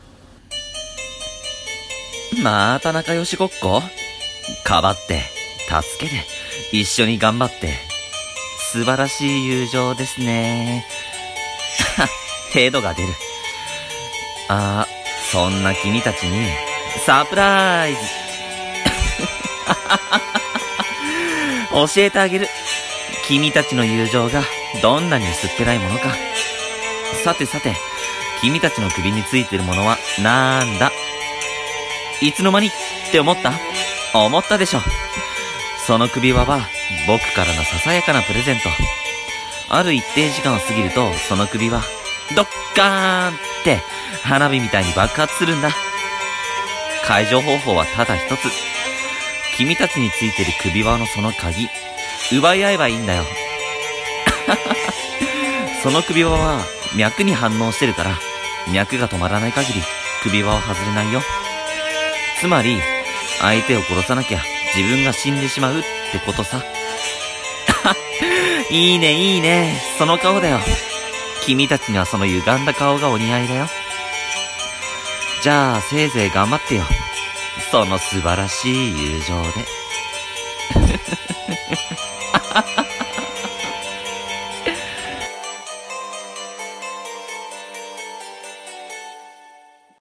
【一人声劇】教えてあげる【悪役】